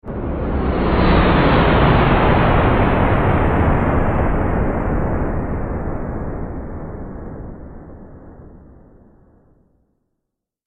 دانلود آهنگ موشک 8 از افکت صوتی حمل و نقل
دانلود صدای موشک 8 از ساعد نیوز با لینک مستقیم و کیفیت بالا
جلوه های صوتی